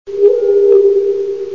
BSG Centurion - Eye Scanner 01
BSG_Centurion-Eye_Scan_01.mp3